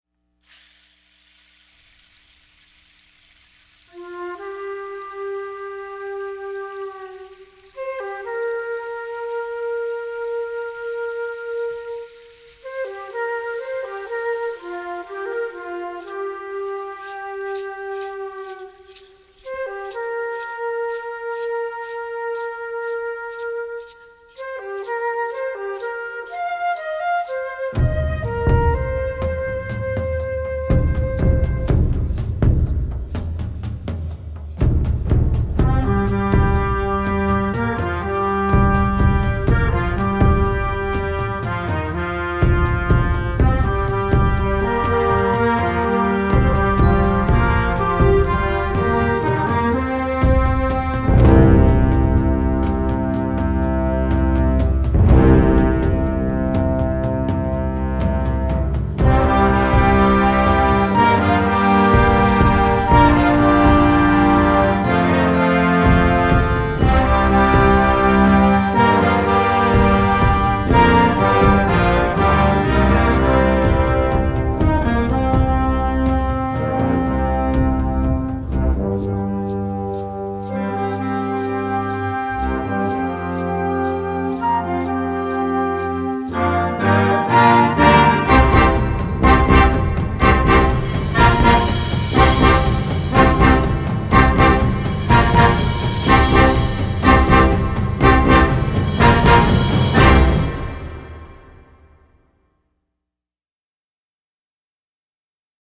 Concert Band Ou Harmonie